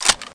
assets/pc/nzp/sounds/weapons/mp5k/magin.wav at af6a1cec16f054ad217f880900abdacf93c7e011